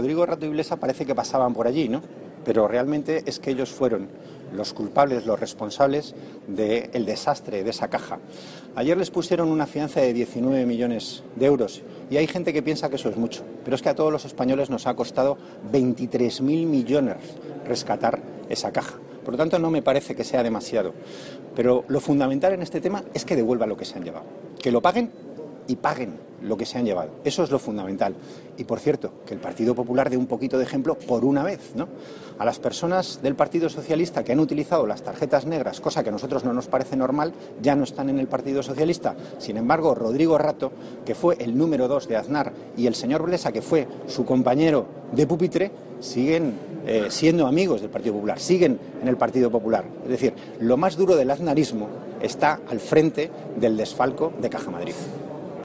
Fragmento de la rueda de prensa de Antonio Hernando en Sevilla.